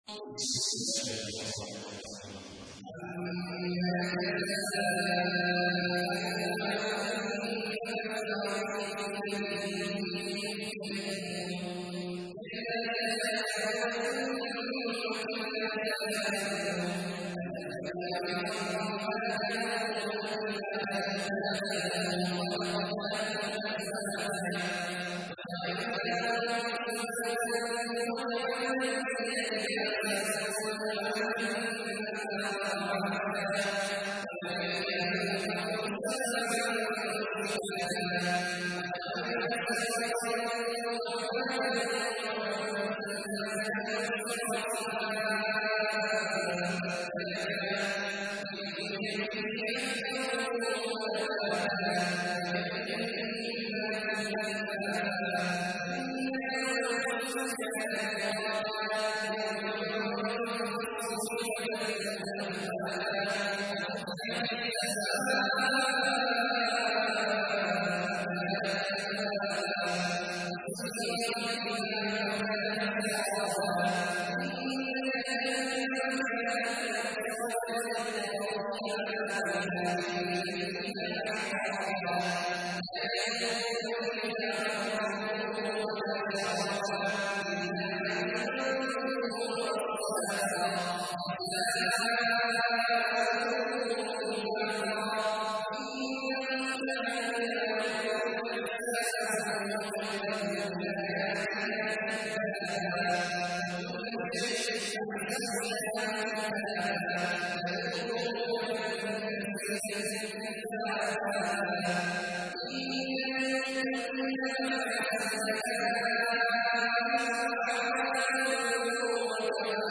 تحميل : 78. سورة النبأ / القارئ عبد الله عواد الجهني / القرآن الكريم / موقع يا حسين